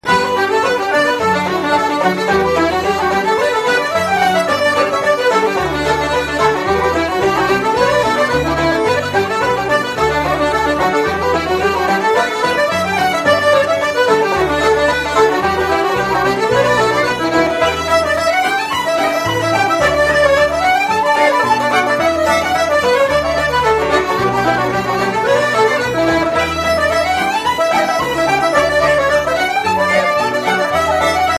banjo,mandolin and guitar
(reels)